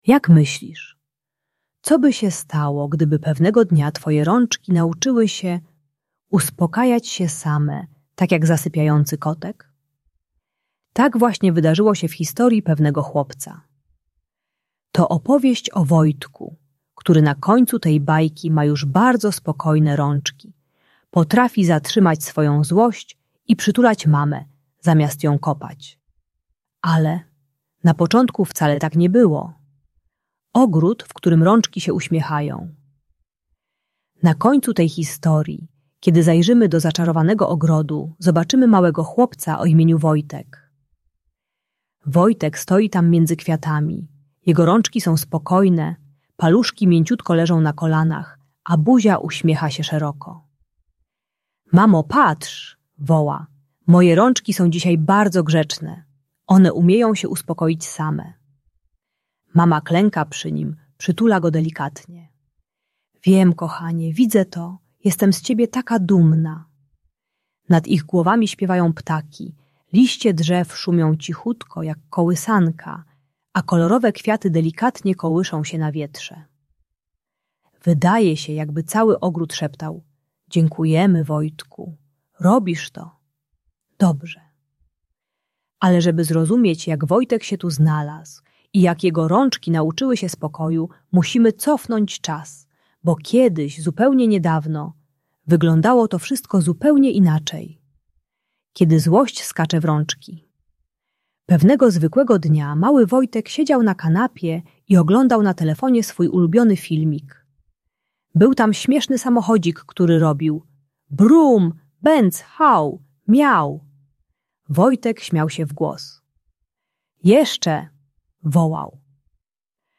Bajka dla dziecka które bije rodziców - przeznaczona dla przedszkolaków w wieku 3-6 lat. Ta audiobajka o agresji wobec mamy i taty uczy techniki "Rączki STÓJ!" oraz głębokiego oddychania, by zatrzymać złość zanim rączki zaczną bić. Pomaga dziecku zrozumieć, że jego rączki nie są złe - tylko zagubione i potrzebują nauki.